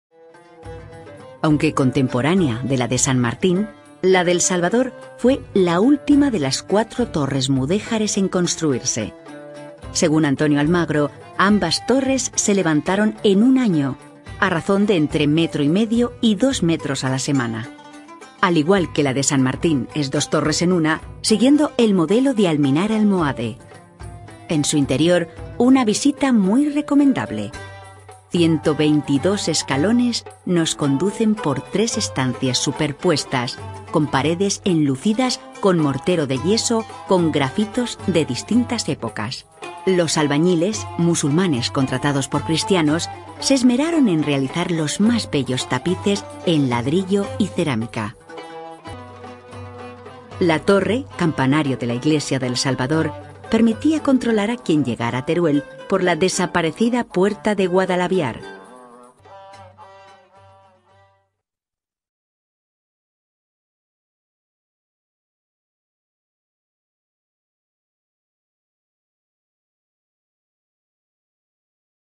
Female
Spanish - Spain (Castilian)
Adult (30-50)
Dulce y cercana. Amable, sensual y rotunda. Con personalidad. Gracias a la interpretación, la voz puede ser cercana y cariñosa o rotunda y dura, dependiendo de la necesidad del texto.
Tour Guide
All our voice actors have professional broadcast quality recording studios.